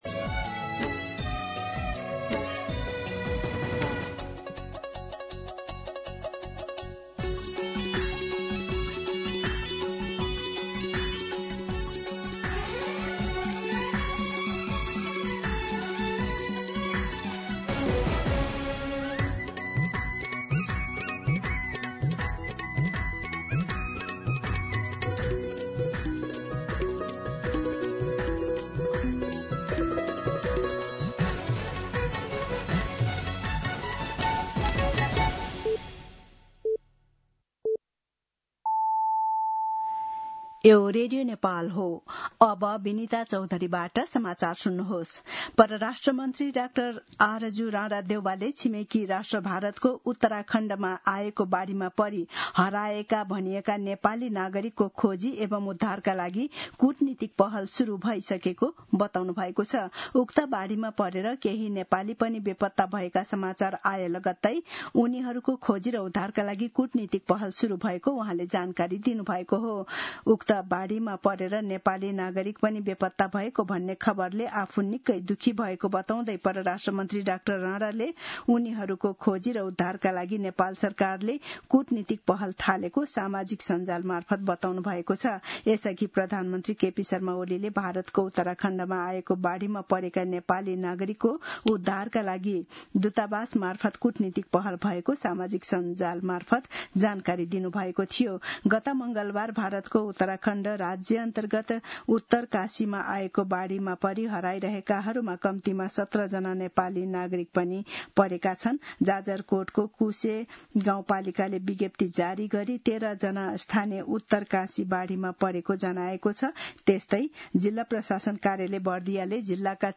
दिउँसो १ बजेको नेपाली समाचार : २३ साउन , २०८२
1-pm-Nepali-News-.mp3